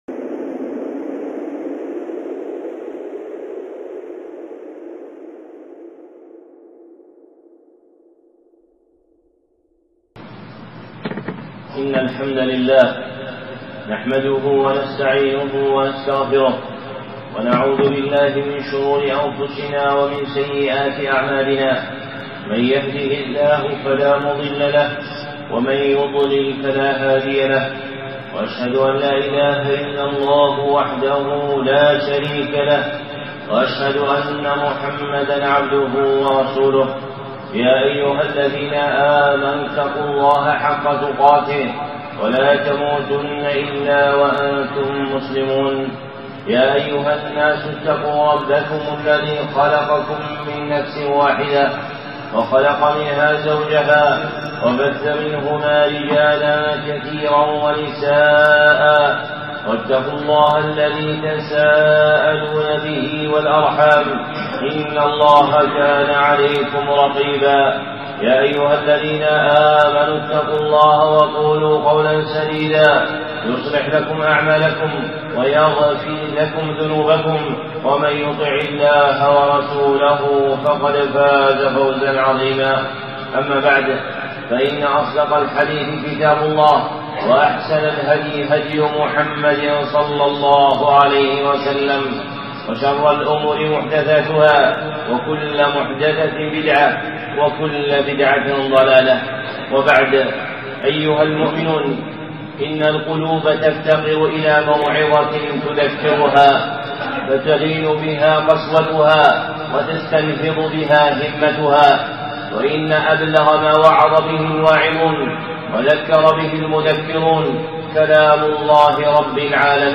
خطبة (موعظة سورة ق)